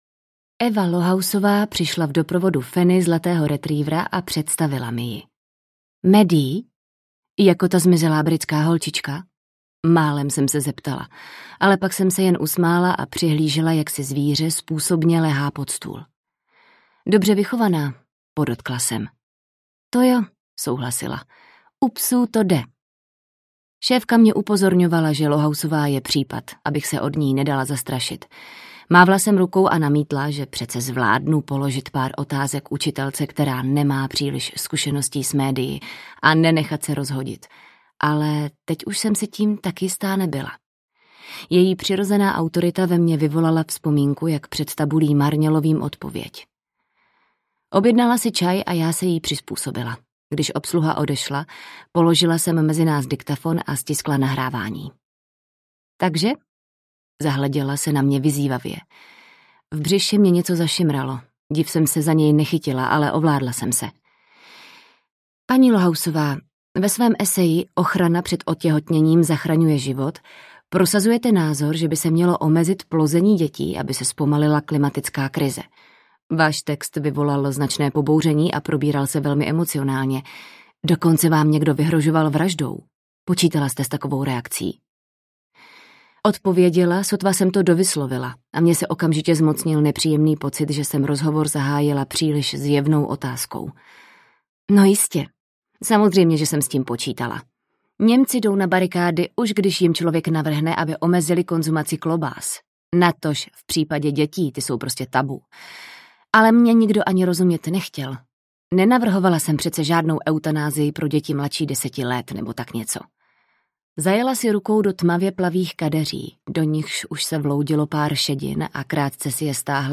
Eva audiokniha
Ukázka z knihy